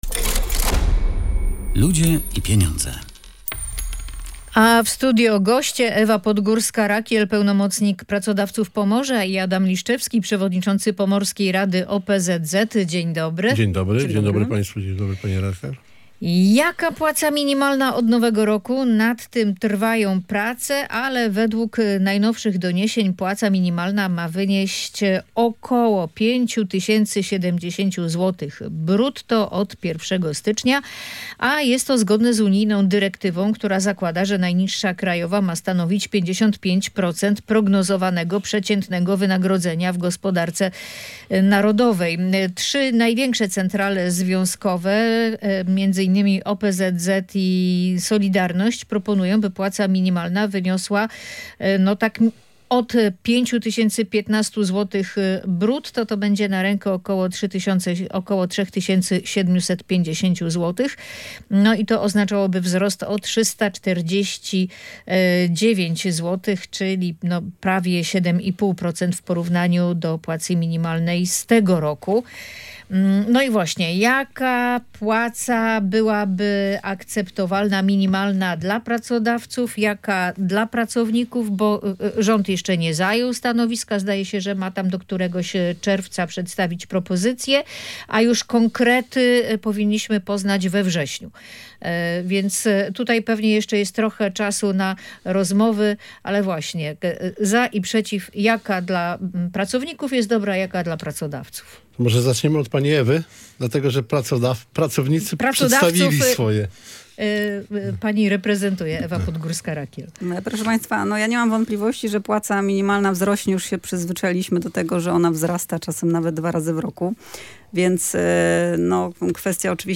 Rozmowę